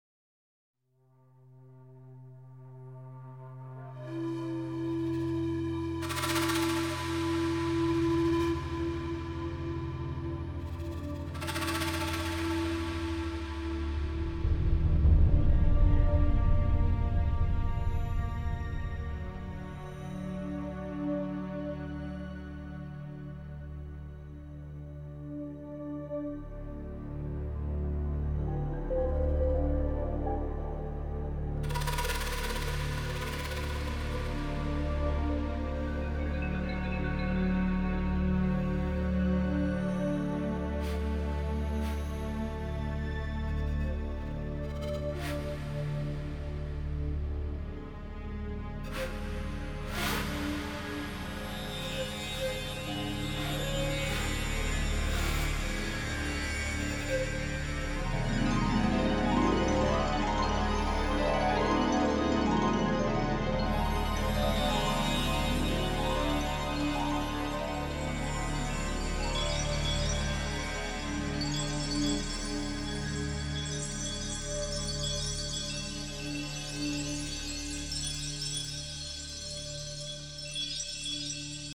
类型:NewAge
流派: Film Soundtracks